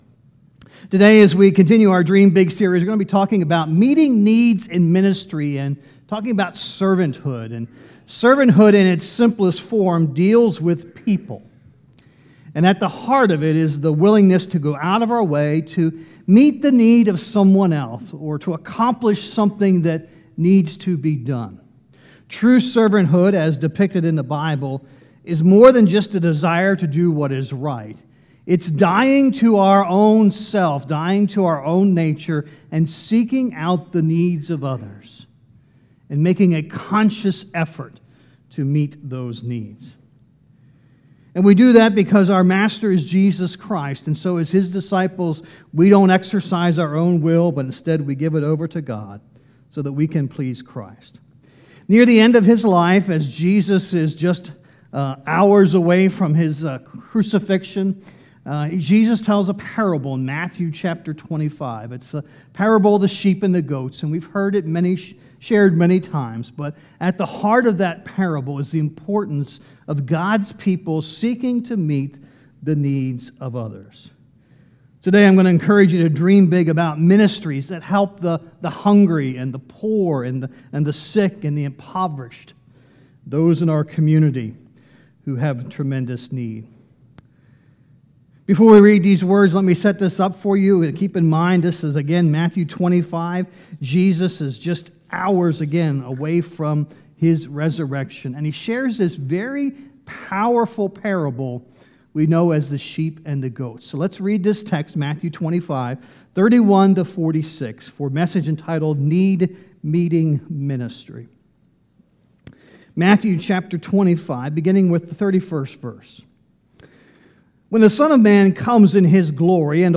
Sermons | Mechanic Grove Church of the Brethren